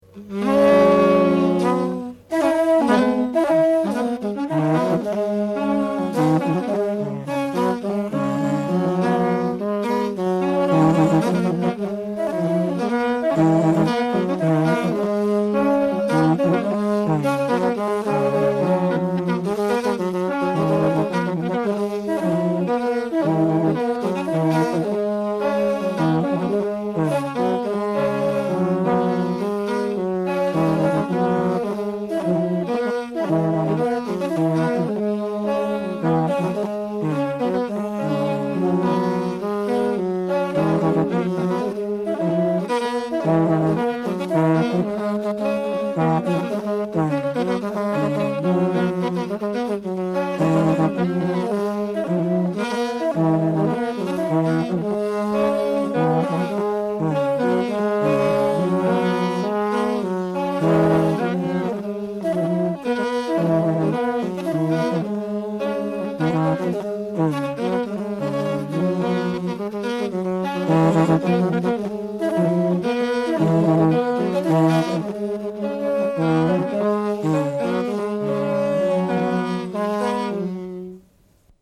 Music--Uganda
Folk music
Field recordings
The Makondere horns are played on the first day of the new moon and also upon important occasions such as the installation of a new Mukama. Horn tune with Makondere gourd horns.